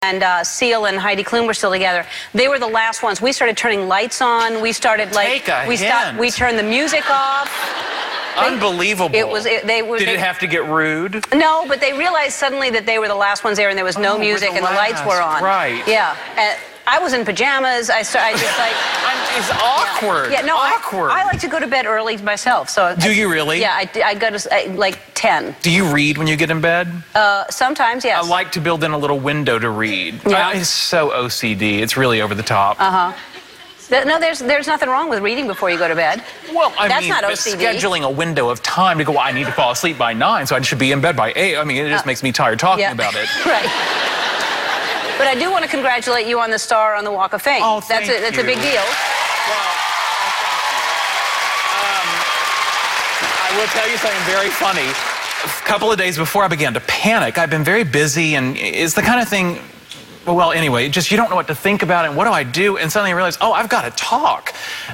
在线英语听力室艾伦脱口秀第117期:难以置信的听力文件下载,艾伦脱口秀是美国CBS电视台的一档热门脱口秀，而主持人Ellen DeGeneres以其轻松诙谐的主持风格备受青睐。